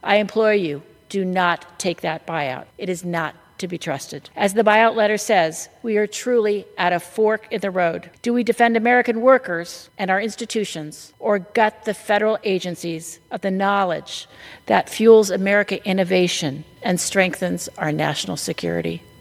Although a federal judge has put a temporary pause in place for the Trump Administration’s buyout plan for federal workers, many lawmakers are still urging a second look at the offer to understand what it means.  Maryland Congresswoman April McClain-Delaney spoke on the House floor, asking workers not to sign on for the plan…